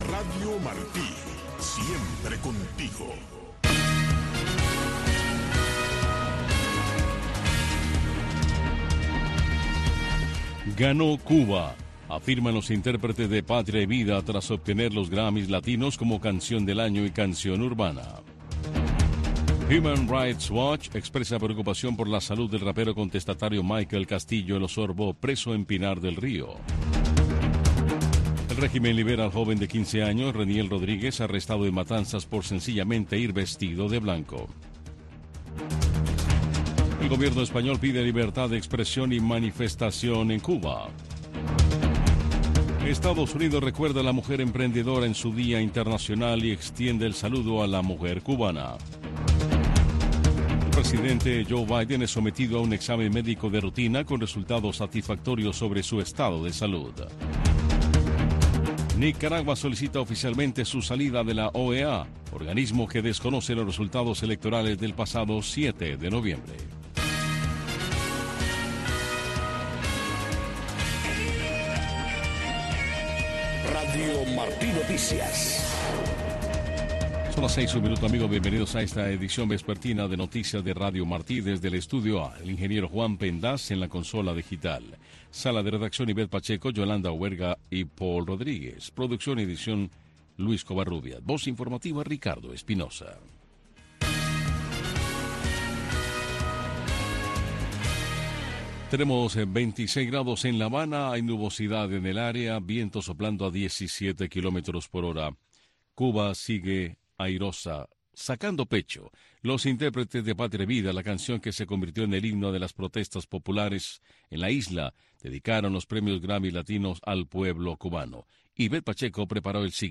Noticiero de Radio Martí